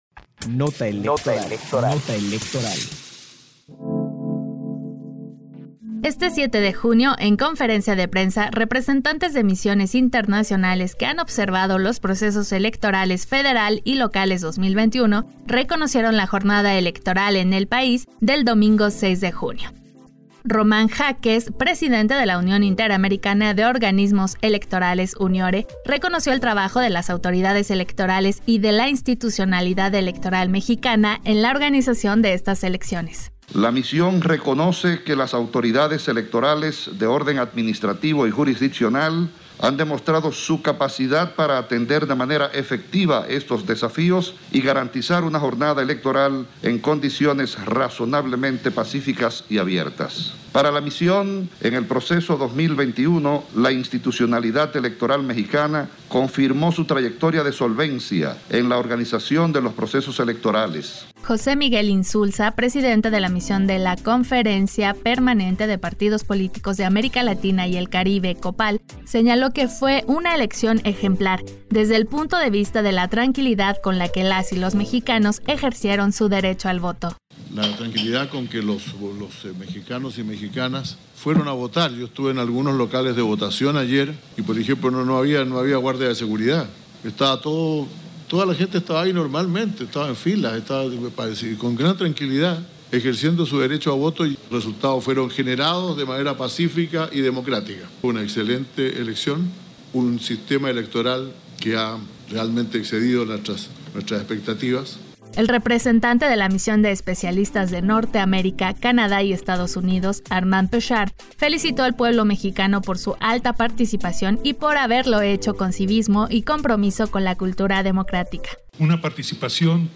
PD_1099_NOTA ELEC_CONFERENCIA OBSERVADORES ELECTORALES 7 junio 2021_CE